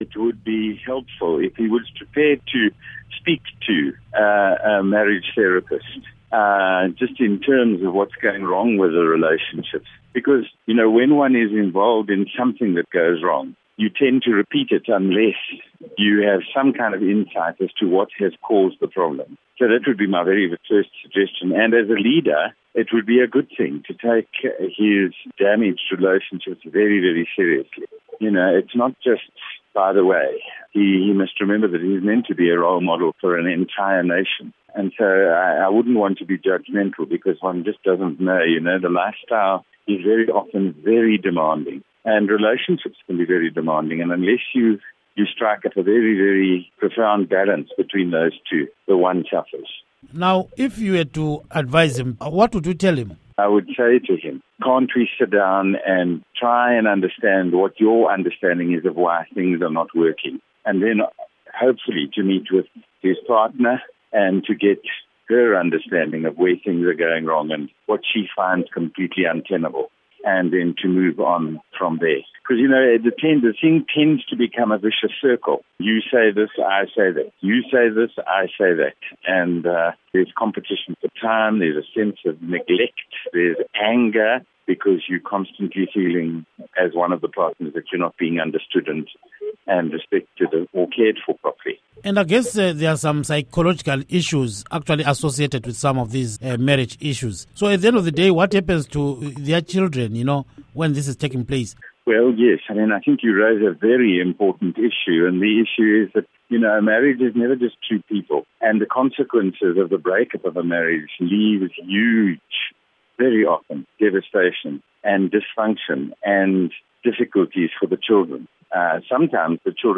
Interview With Bishop Paul Verryn